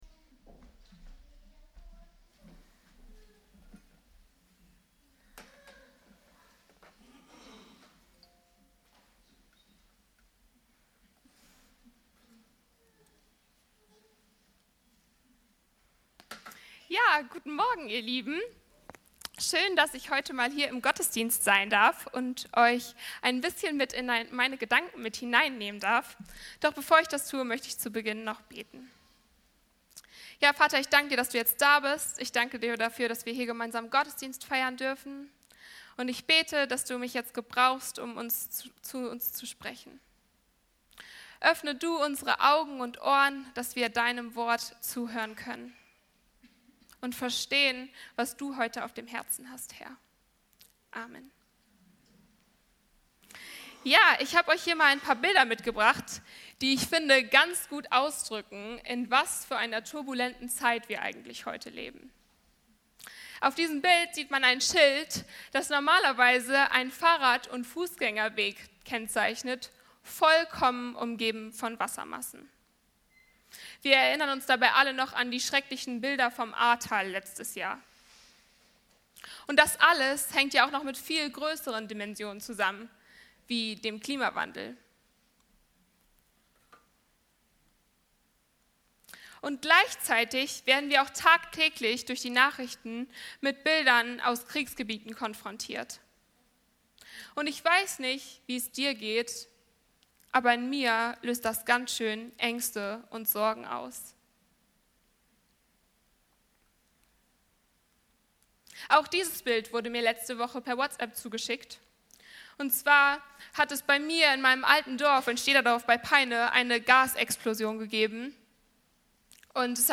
Friede statt Unruhe - FCG-Oldenburg Predigt-Podcast